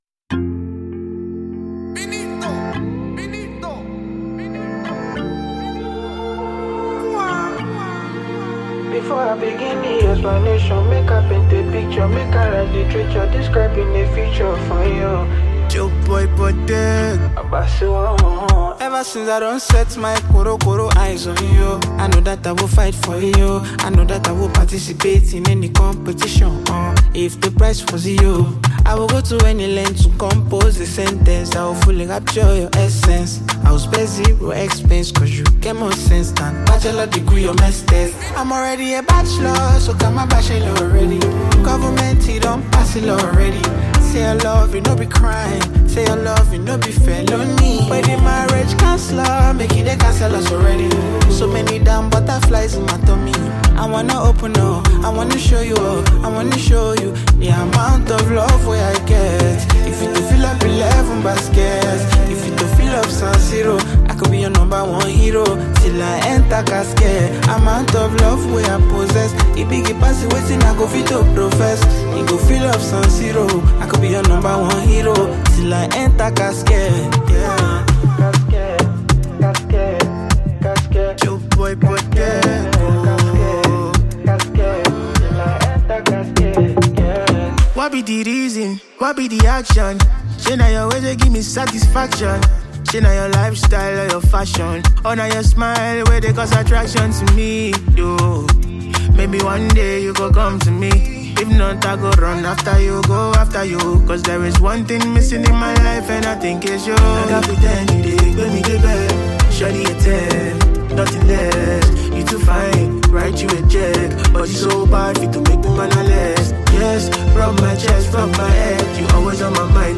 Nigerian music singer and songwriter